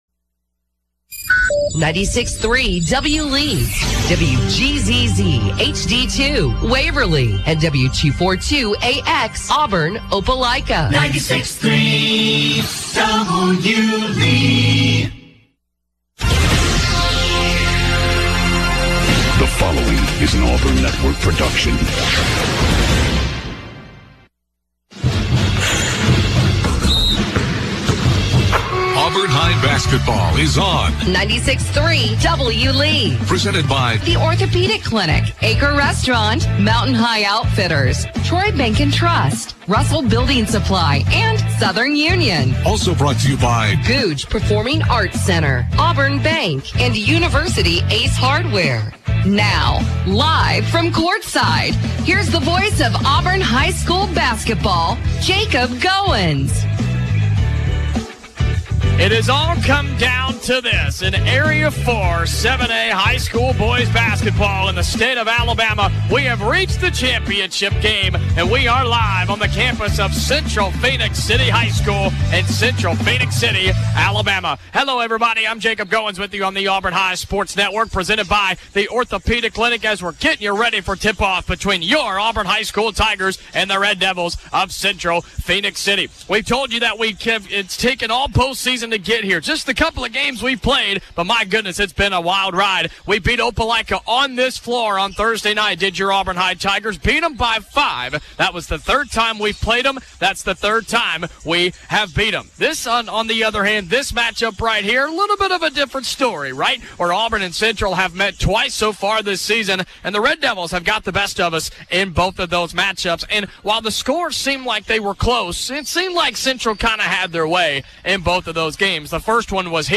calls Auburn High's game versus the Central Red Devils in the Area Championship game.